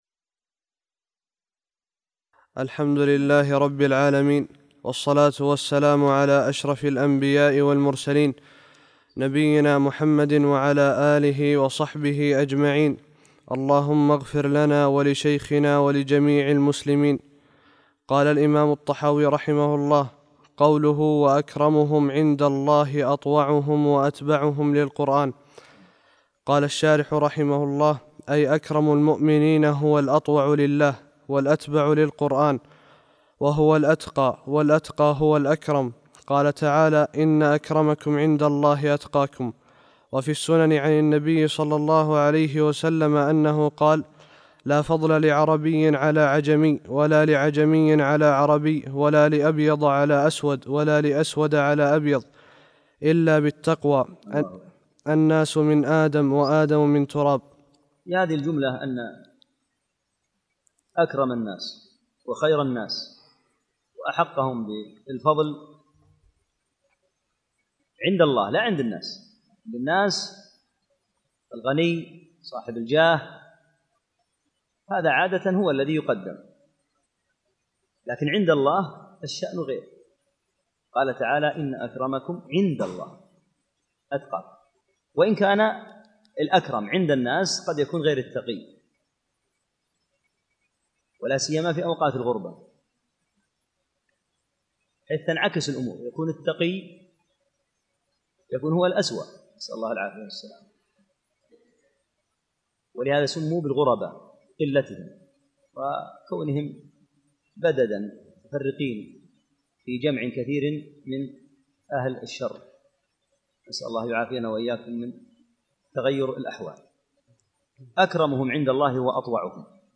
8- الدرس الثامن